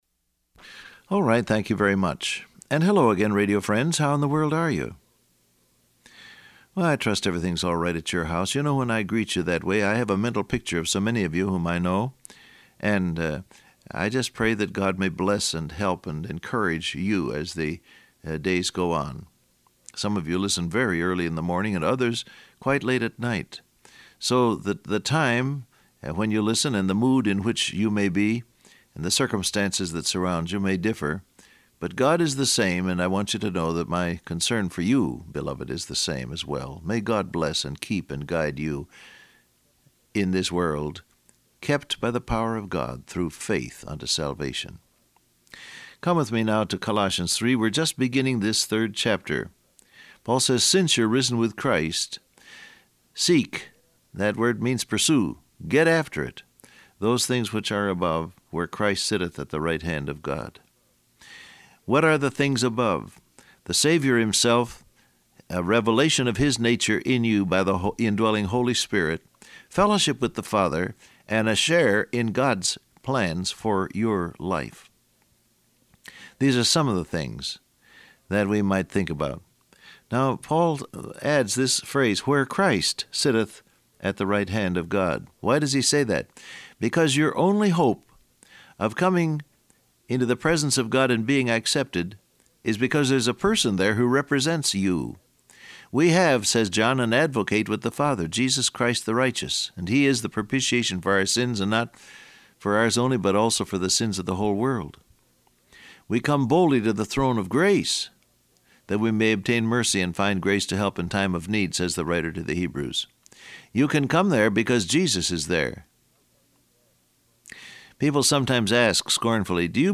Download Audio Print Broadcast #1901 Scripture: Colossians 3:1 , Isaiah 1, Colossians 2:22 Transcript Facebook Twitter WhatsApp Alright, thank you very much.